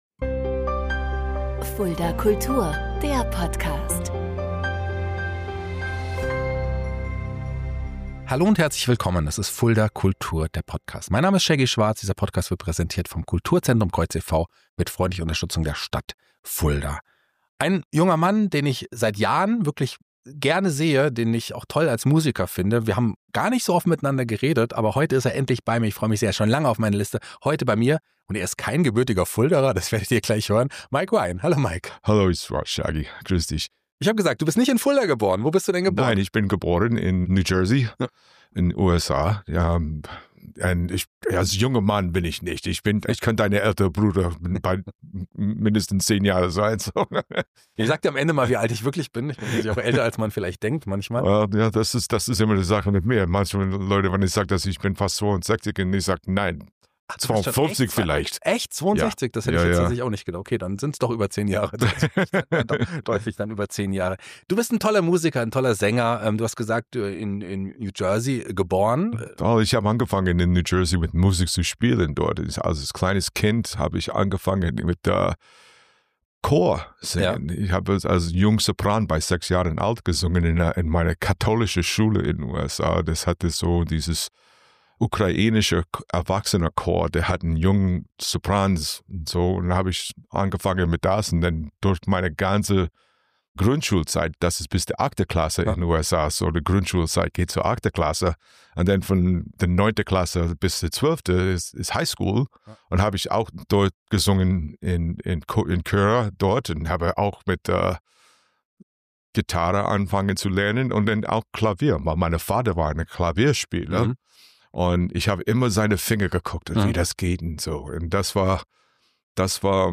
Ein warmes, persönliches Gespräch über Identität, Musik, Humor, Heimat – und die Liebe zu Springsteen.